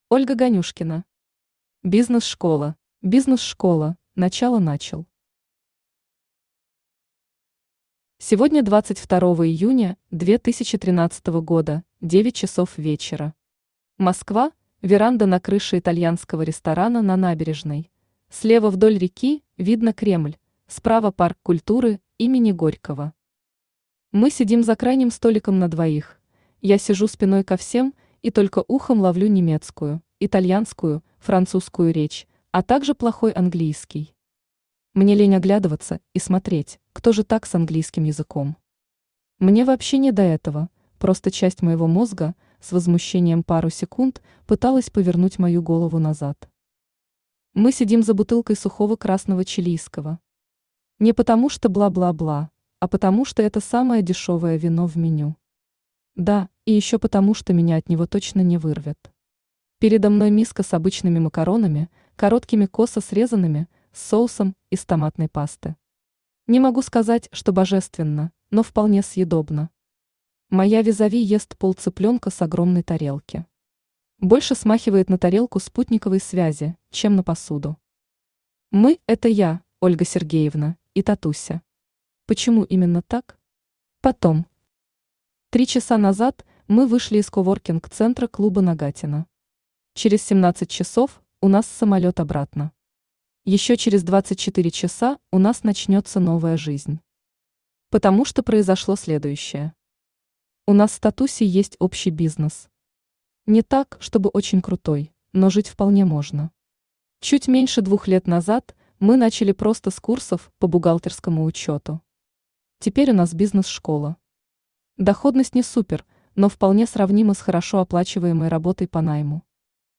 Aудиокнига Бизнес-школа Автор Ольга Станиславовна Ганюшкина Читает аудиокнигу Авточтец ЛитРес.